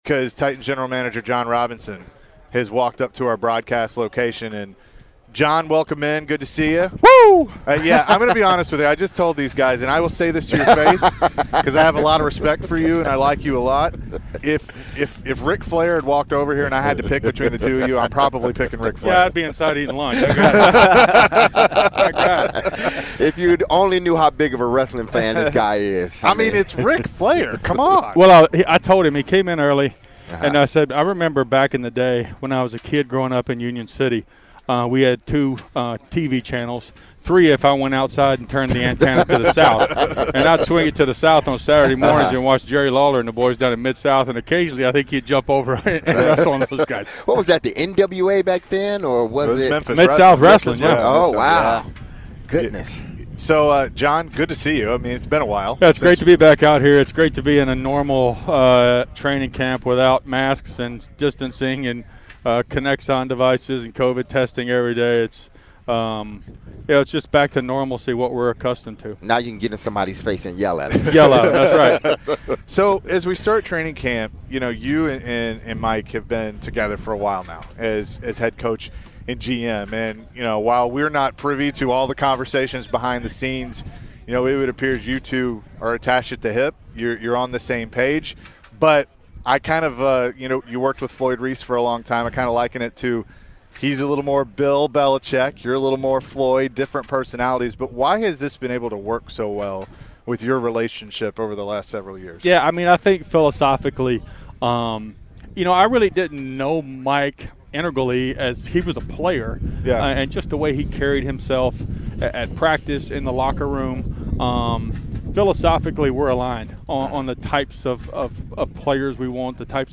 Jon Robinson Full Interview (07-28-22)